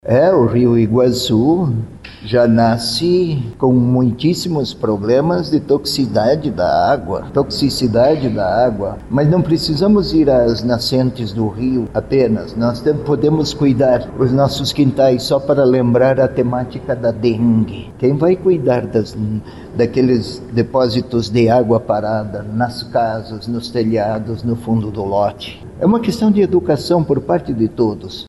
A divulgação deste ano aconteceu durante coletiva de imprensa, na Cúria Metropolitana, na capital.